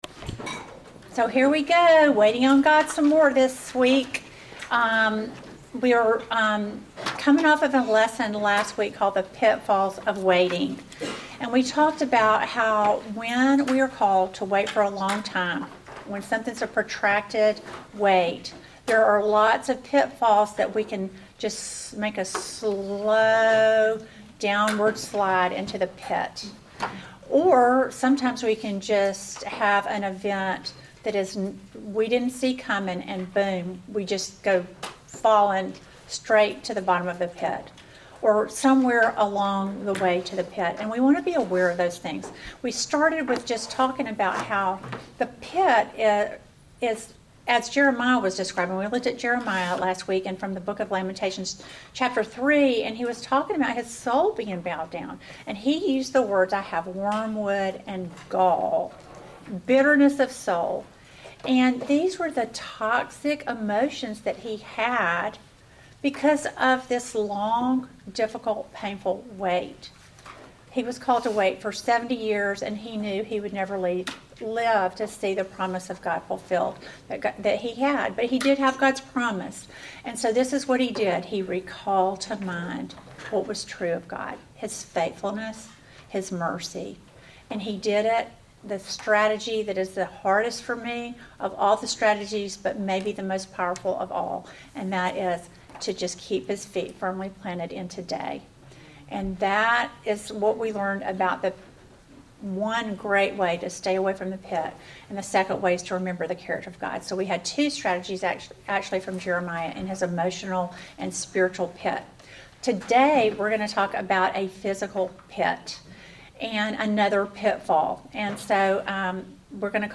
Welcome to the twelfth lesson in our series WAITING ON GOD!